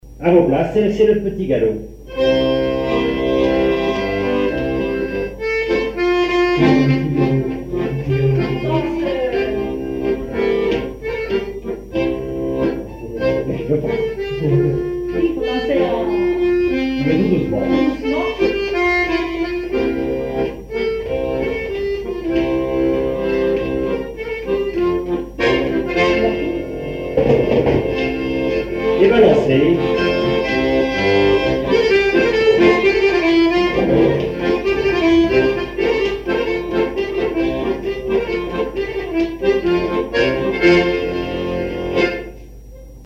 danse : quadrille : petit galop
répertoire à l'accordéon diatonique
Pièce musicale inédite